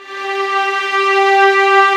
Index of /90_sSampleCDs/Roland LCDP13 String Sections/STR_Violins I/STR_Vls2 Arco